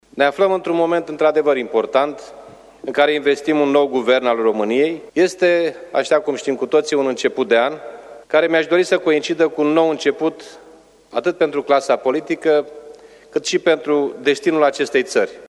A luat apoi cuvântul președintele Camerei Deputaților, Liviu Dragnea:
În sală sunt prezenţi parlamentari, miniştrii propuşi, dar şi reprezentanţi ai instituţiilor statului – preşedintele Curţii de Conturi, Avocatul Poporului, reprezentanţi ai corpului diplomatic şi ai cultelor religioase.